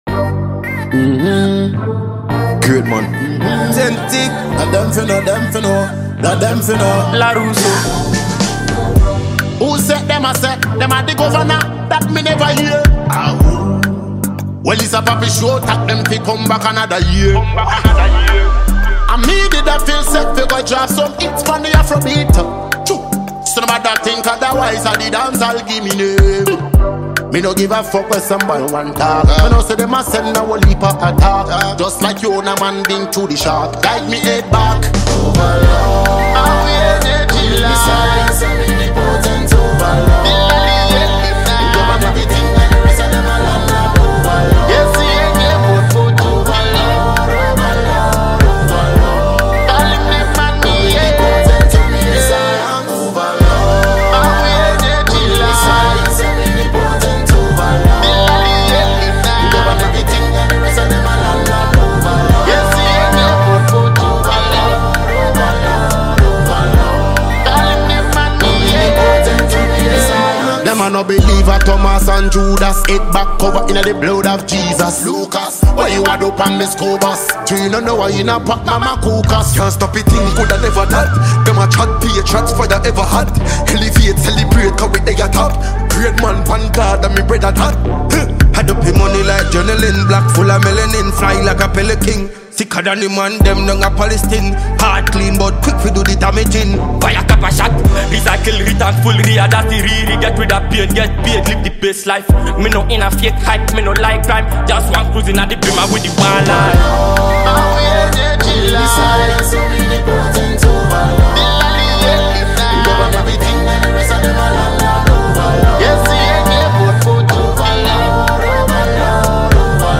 Multiple award-winning Ghanaian Afro-dancehall musician